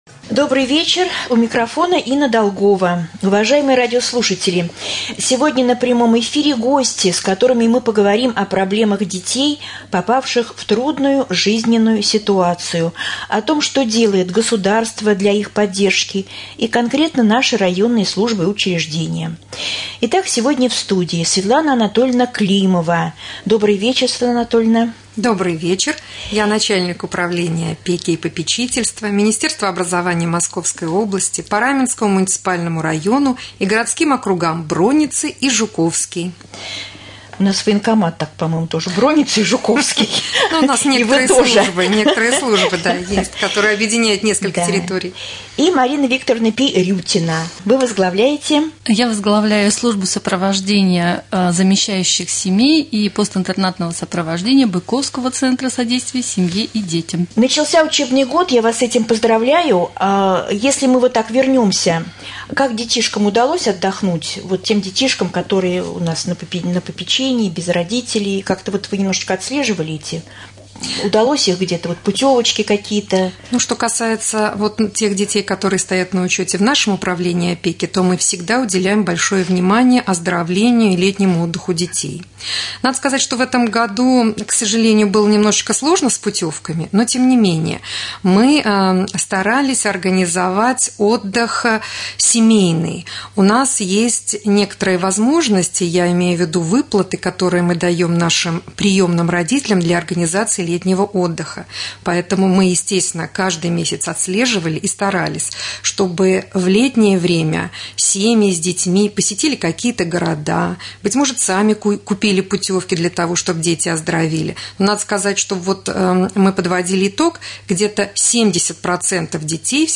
Гости студии
Прямой эфир